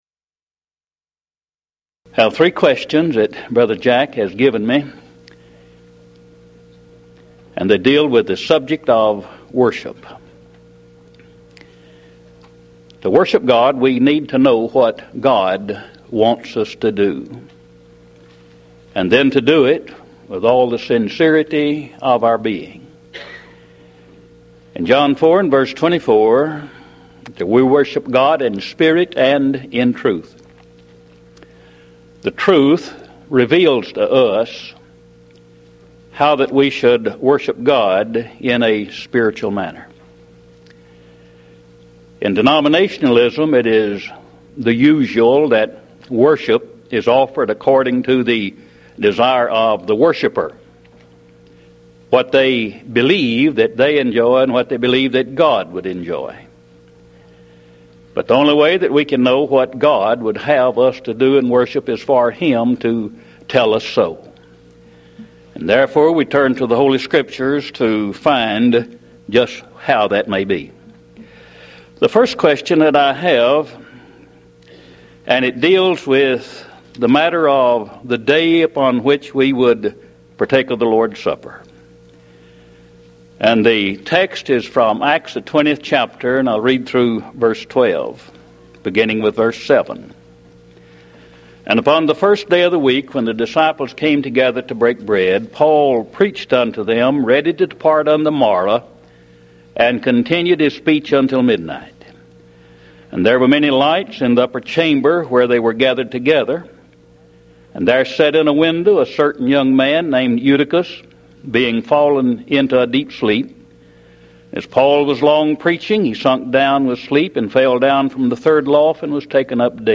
Event: 1995 Mid-West Lectures
lecture